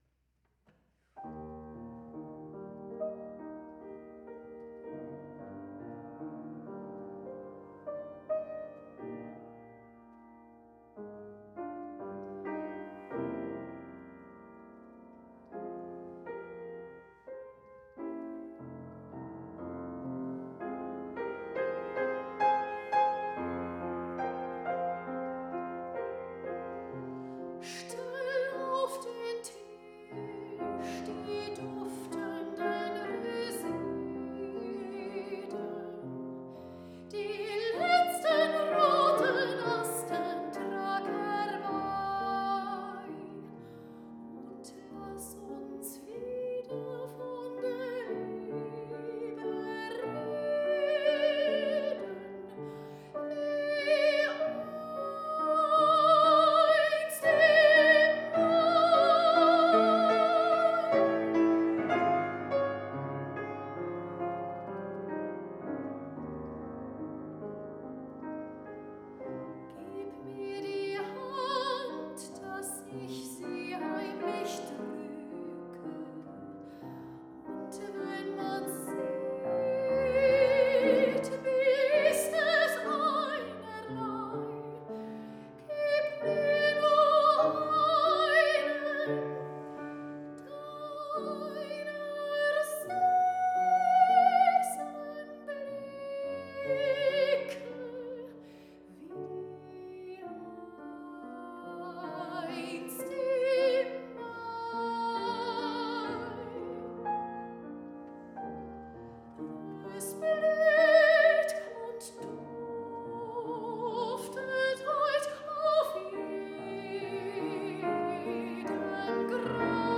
Sängerin | Sopran